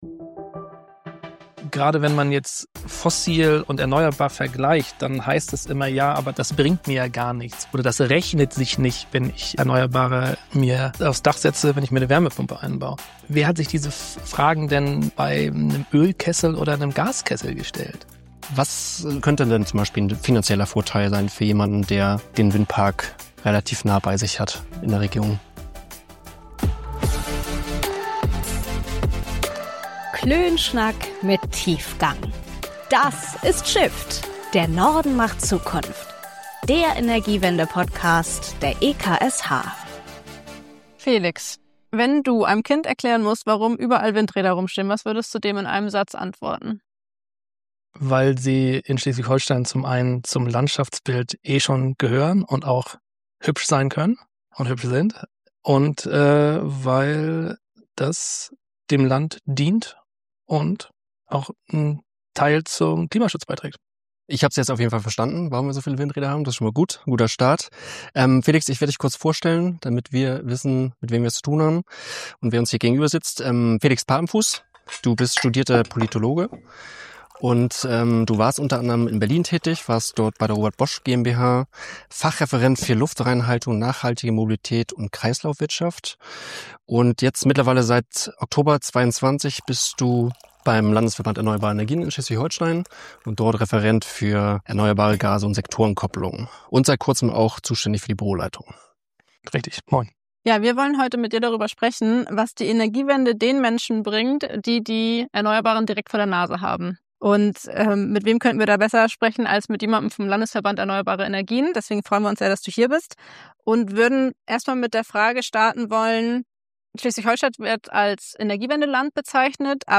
Viel Spaß bei unserem Klönschnack mit Tiefgang!